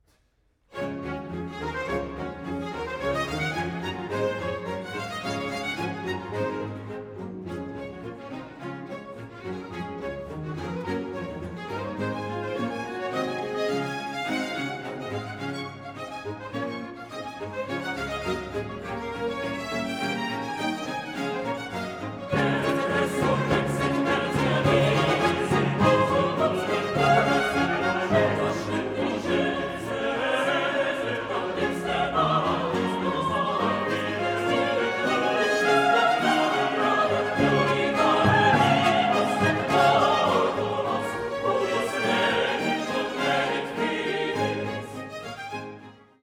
soprano
alto
tenor
bass